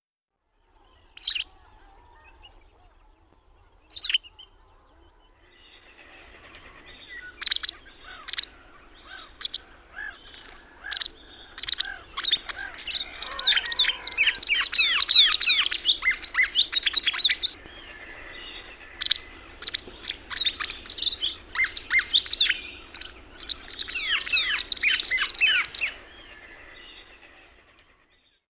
Västäräkki